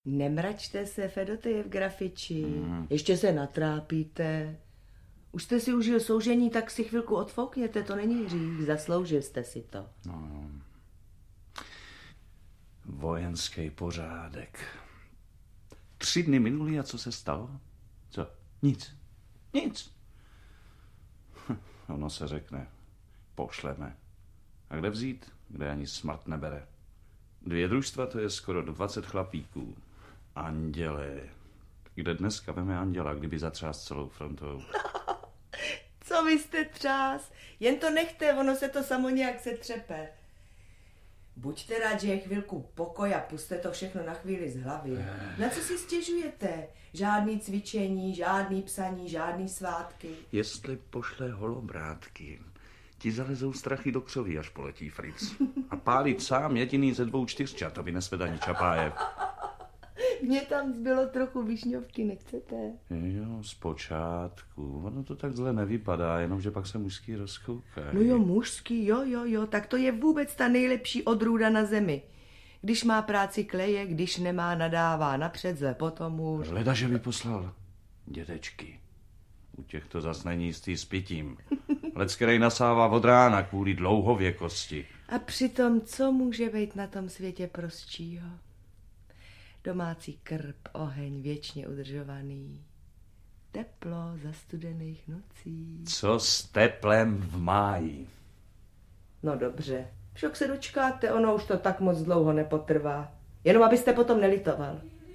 Audiokniha Významné inscenace Vinohradského divadla - obsahuje ukázky z různých představení Vinohradského divadla.